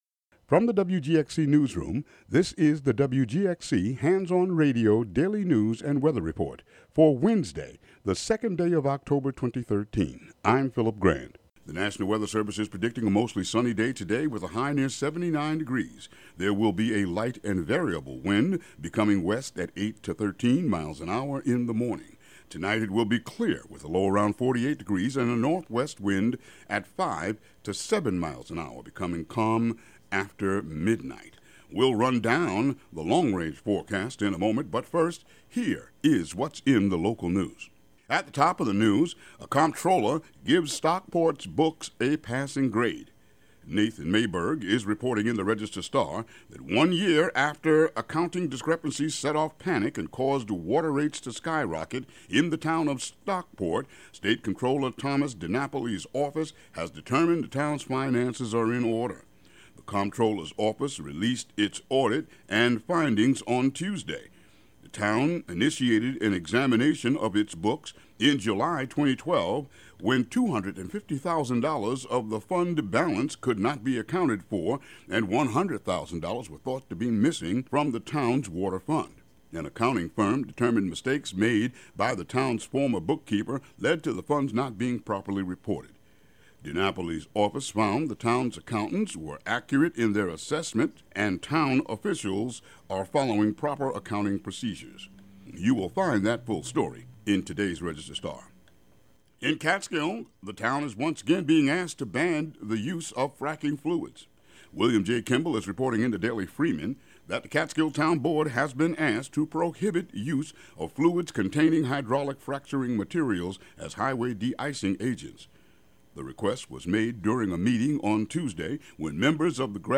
Local news and weather for Wednesday, October 2, 2013.